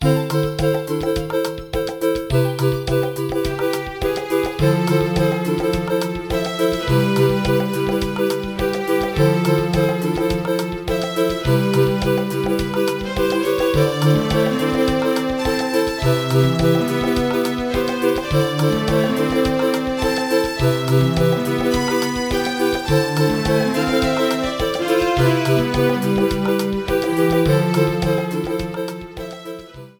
Shortened, fadeout